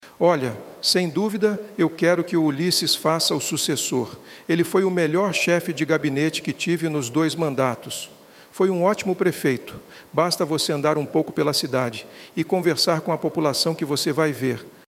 Feitos por Inteligência Artificial, os áudios imitam trejeitos da fala do político e afirmam um descontentamento com a possível candidatura dele para prefeito municipal nas Eleições 2024.
No outro áudio, a voz falsa de Silvio Barros torce para que o atual prefeito de Maringá, Ulisses Maia, faça um sucessor nas Eleições 2024.